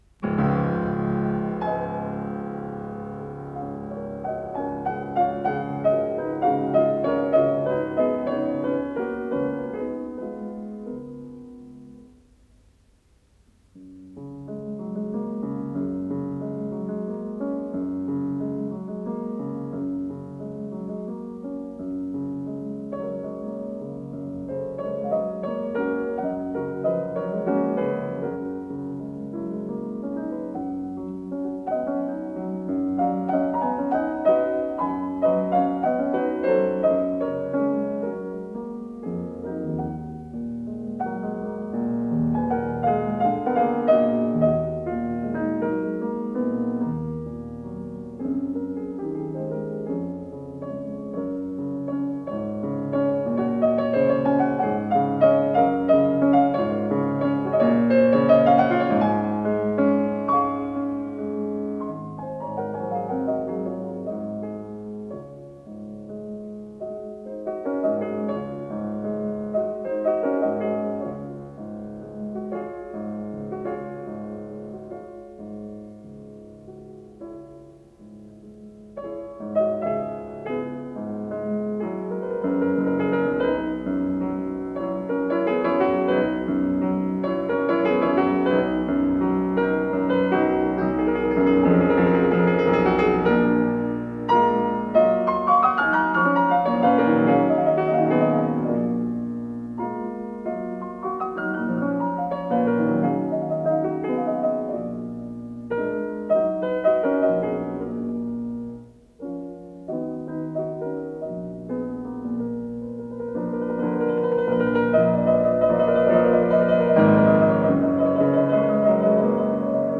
с профессиональной магнитной ленты
ИсполнителиОксана Яблонская - фортепиано
ВариантДубль моно